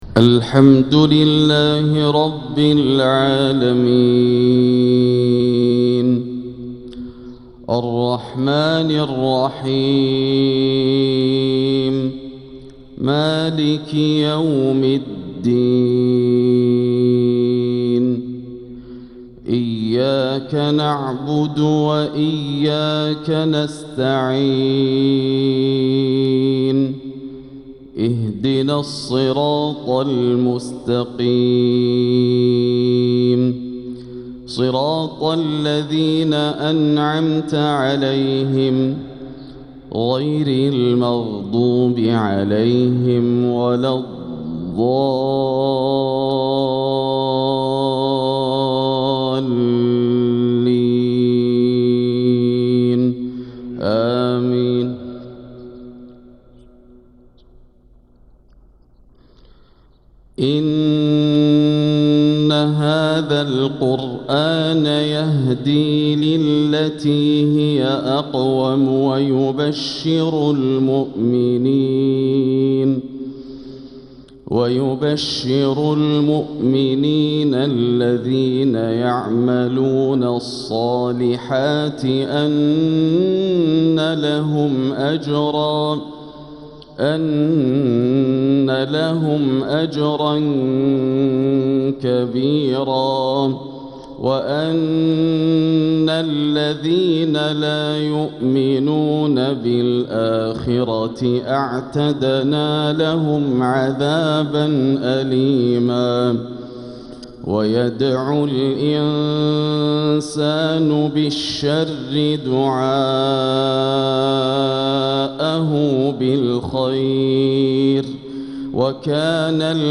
تلاوة هادئة من سورة الإسراء | فجر الأحد 3-8-1446هـ > عام 1446 > الفروض - تلاوات ياسر الدوسري